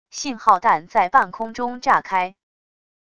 信号弹在半空中炸开wav音频